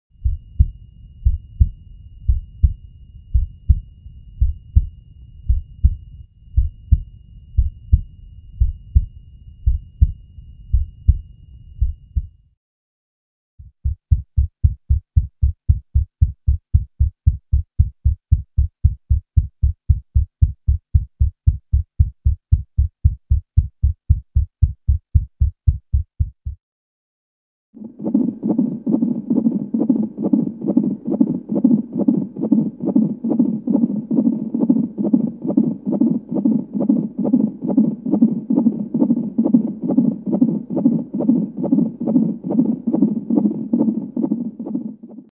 دانلود صدای ضربان قلب کودک از ساعد نیوز با لینک مستقیم و کیفیت بالا
جلوه های صوتی